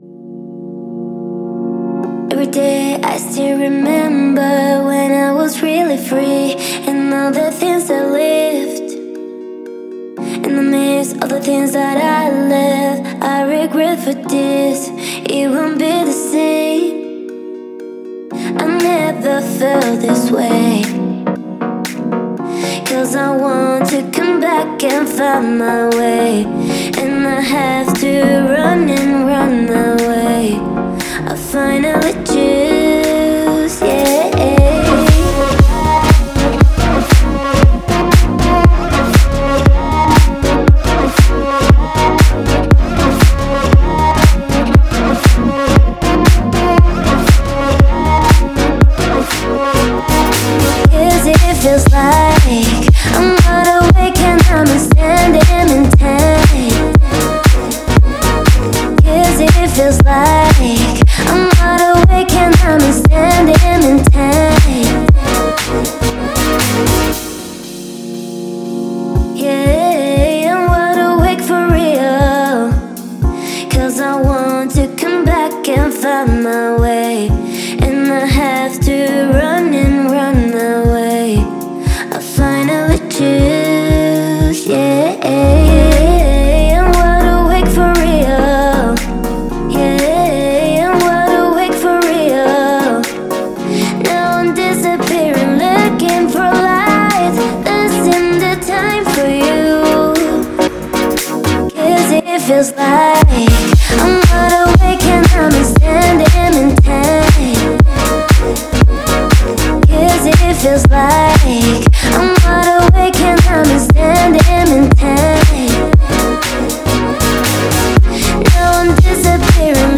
трек в жанре поп с элементами электронной музыки